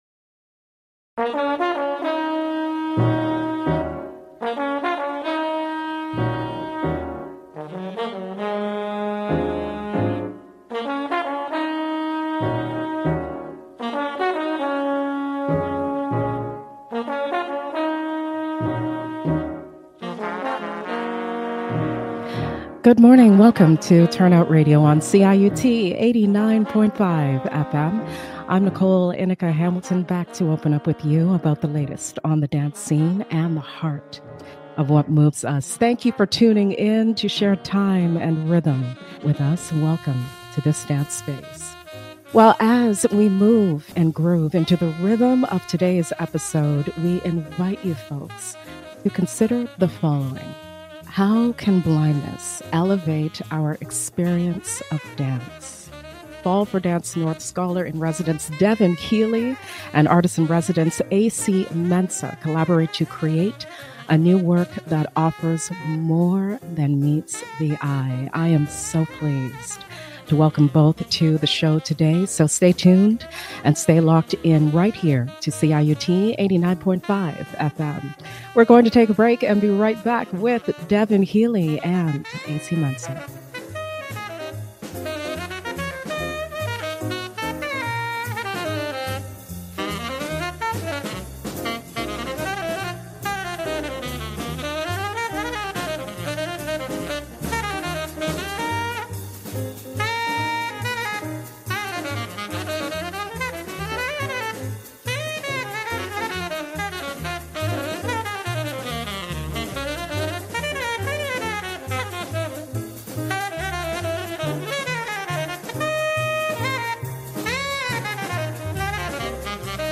L ive on CIUT 89.5 FM